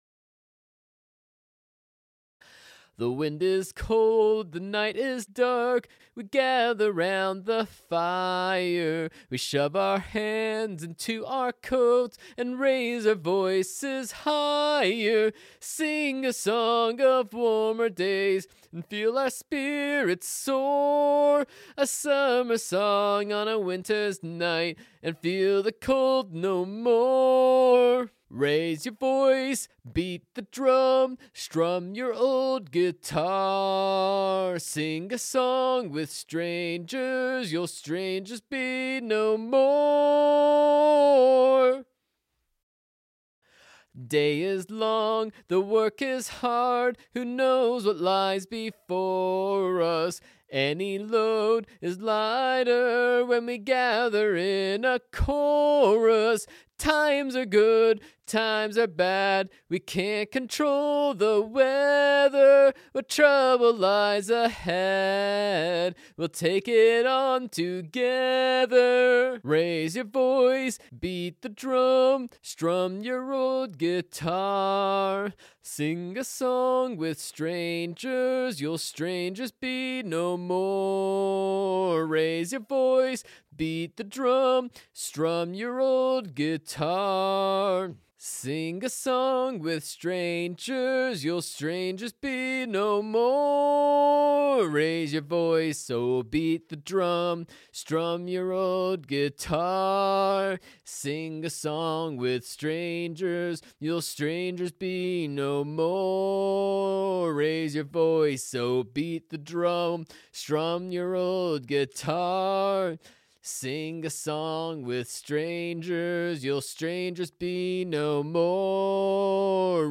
a_capella_song_1.mp3